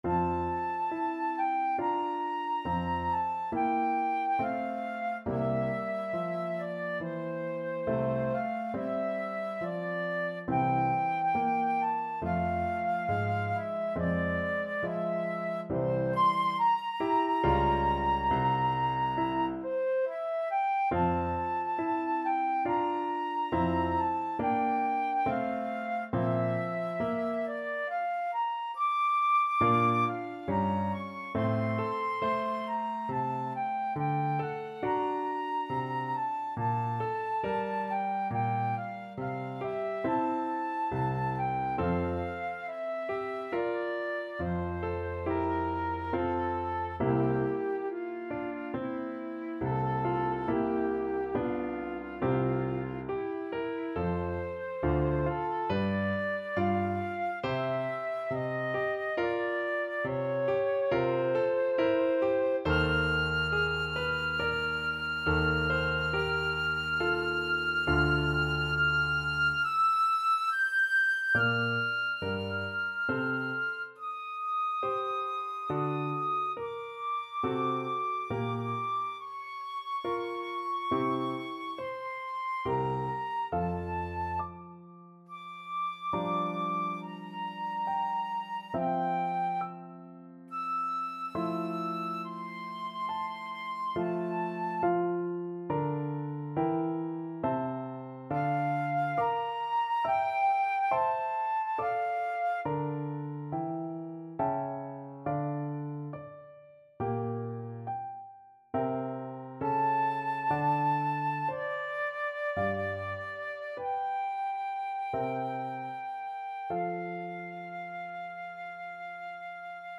Classical Brahms, Johannes Piano Concerto No.2, Op. 83, Slow Movement Main Theme Flute version
F major (Sounding Pitch) (View more F major Music for Flute )
6/4 (View more 6/4 Music)
Andante =c.84 =69
Classical (View more Classical Flute Music)